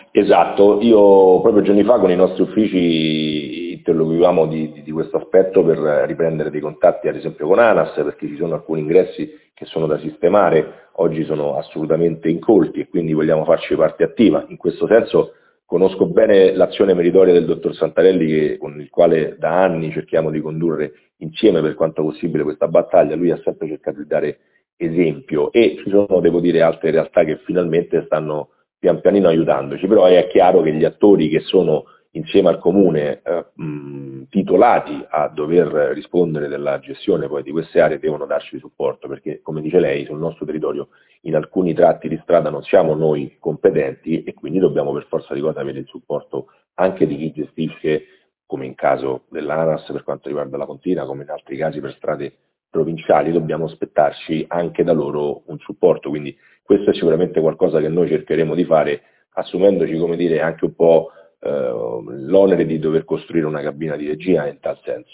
Il vice sindaco e assessore all’ambiente, Vittorio Marchitti, si è dichiarato pronto a proporre il suo assessorato come cabina di regia.
Ascoltiamolo ai nostri microfoni